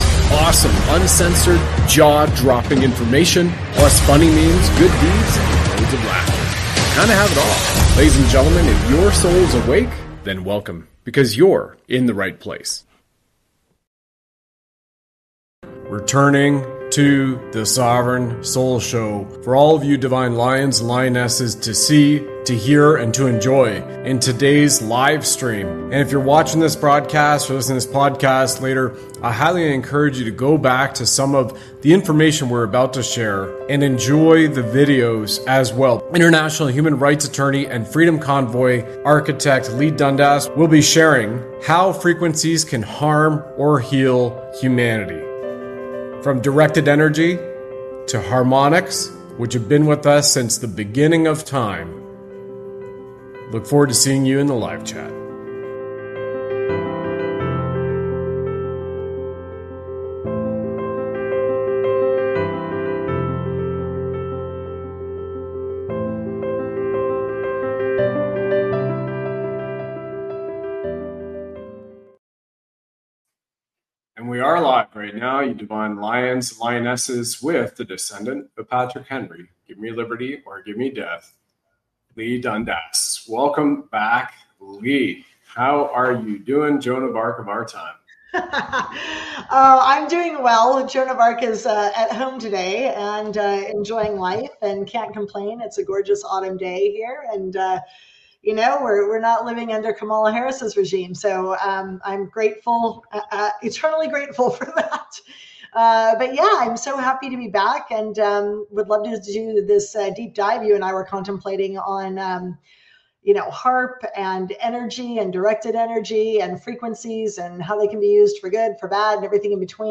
This broadcast discusses the potential harm or healing effects of frequencies, with a focus on the High Altitude Atmospheric Research Project (HARP) in Alaska.